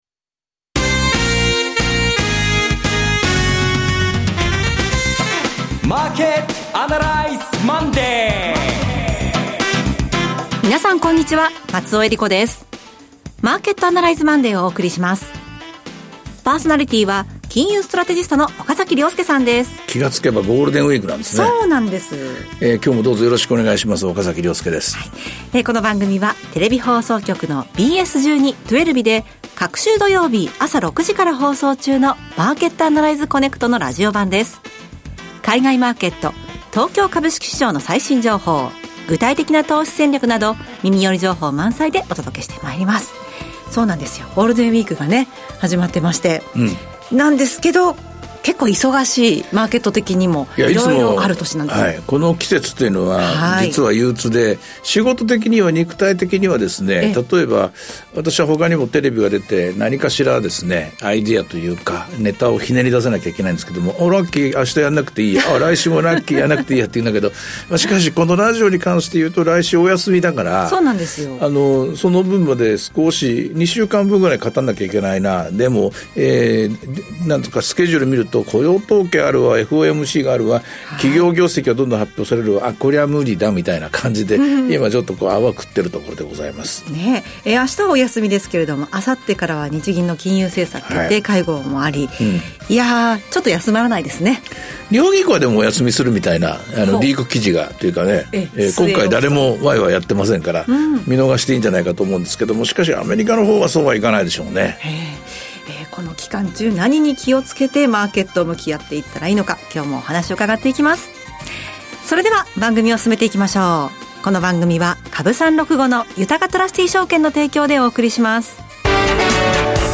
ＢＳ１２ ＴwellＶの「マーケット・アナライズ コネクト」のラジオ版。今週のマーケットはどうなるか？投資家はどう対応すればよいのか、等を２５分間に凝縮してお届けします。